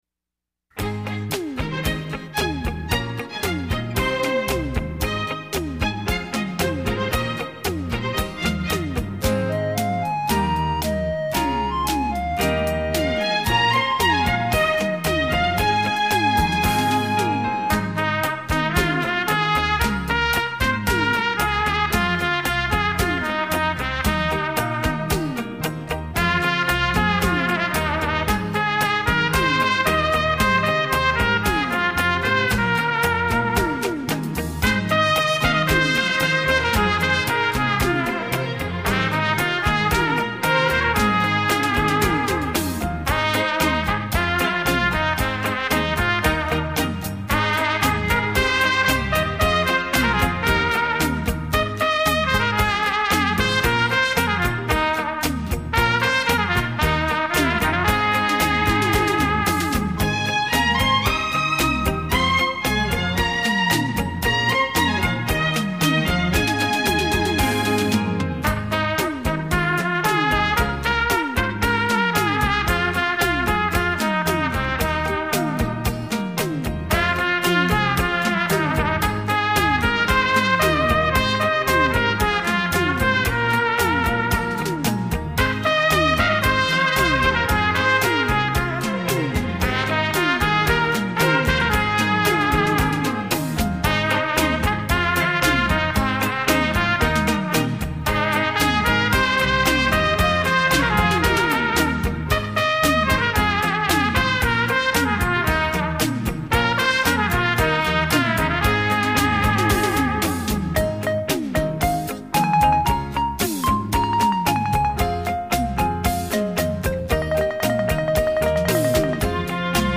火箭筒 重低音
X-BASS数位环绕音效
小喇叭的魅力满点